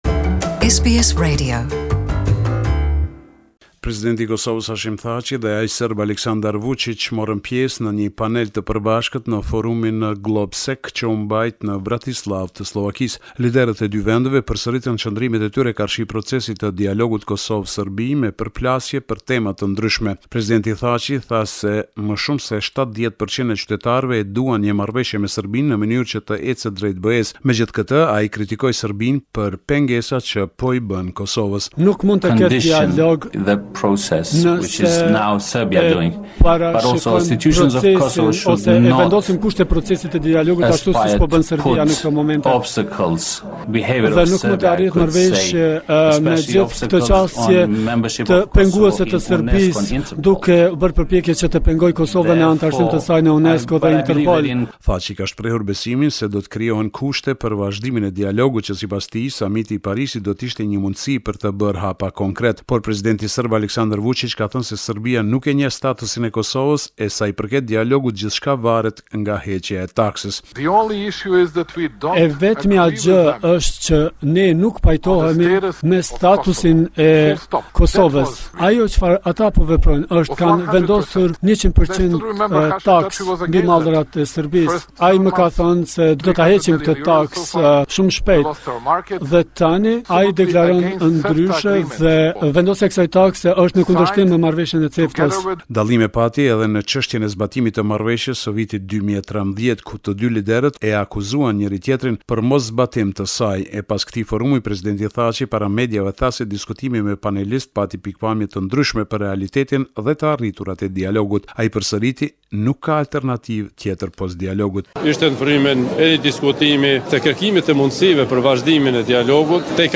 This is a report summarising the latest developments in news and current affairs in Kosova